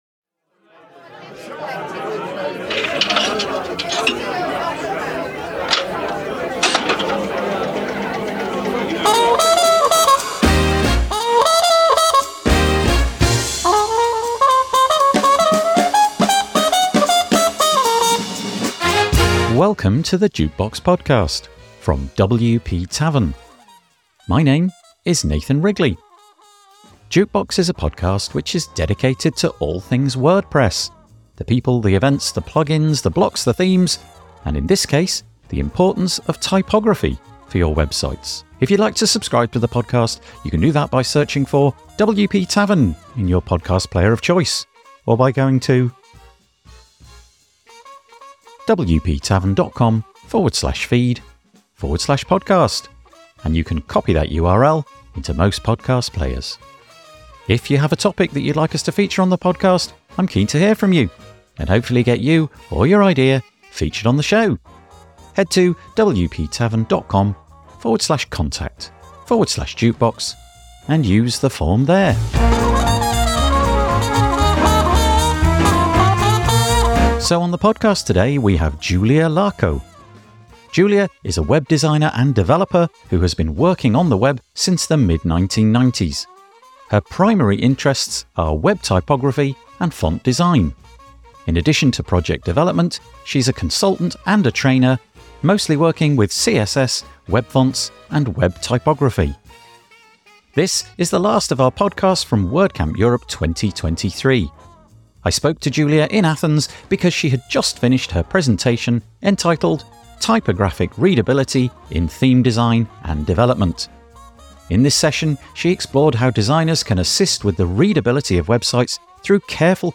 This is the last of our podcasts from WordCamp Europe 2023.